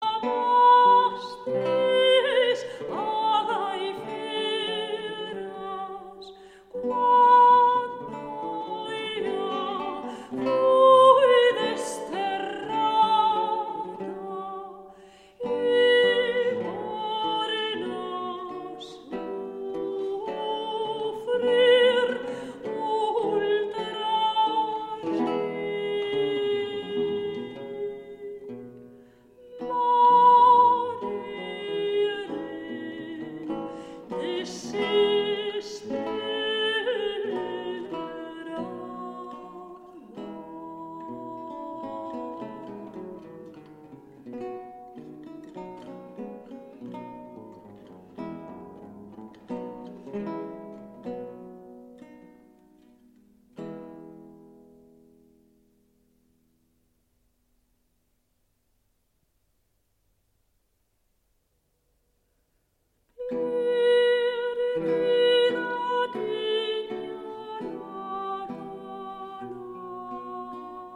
Villancico
~1400 - ~1800 (Renaissance)